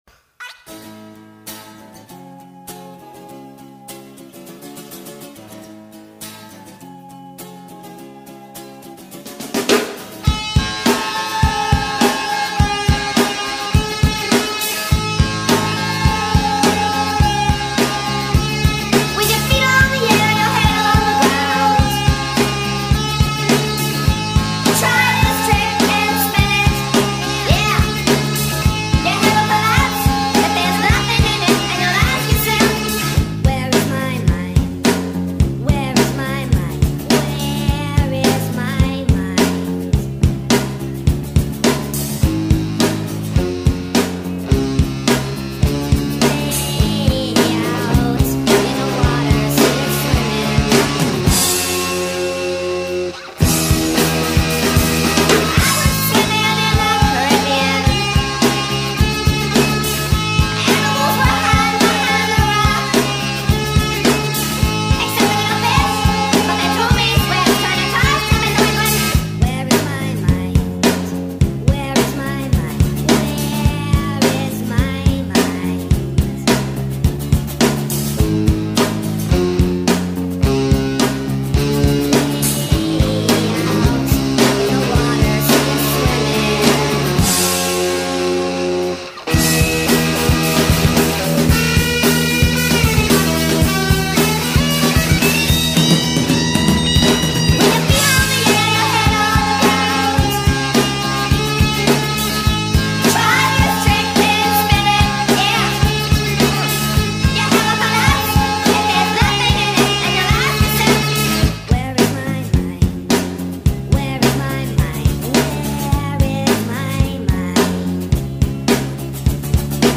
در نسخه سریع شده و Sped Up
غمگین